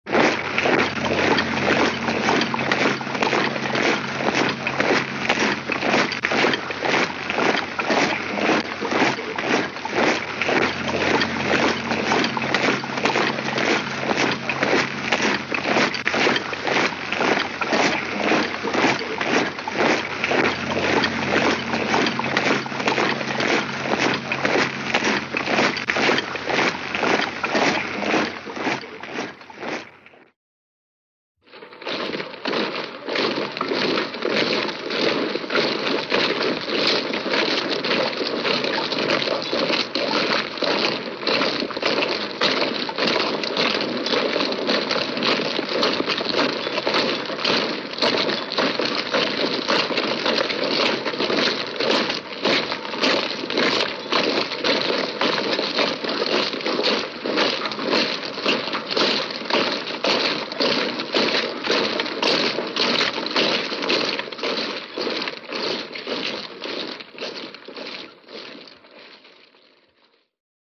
Звуки марша солдат
Солдаты шагают в строю nМарш солдатских сапог nСтройные ряды идут